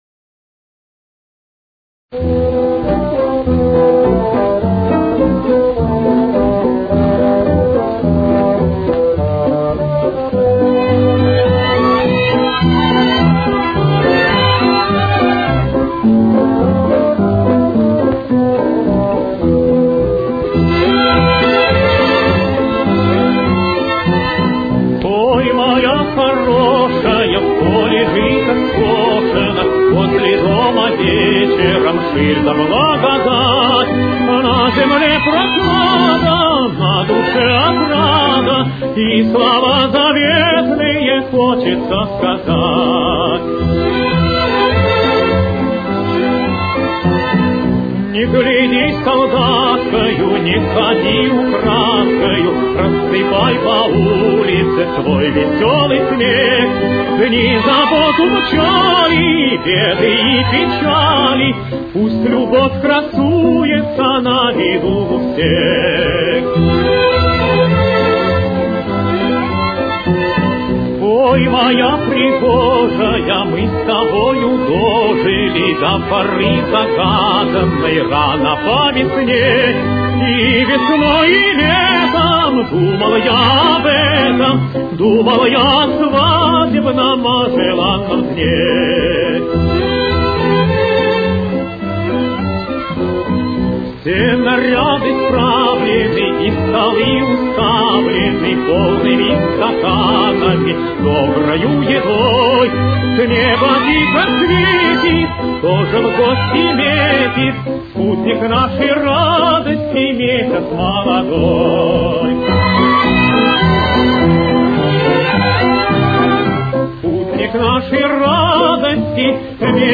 с очень низким качеством (16 – 32 кБит/с)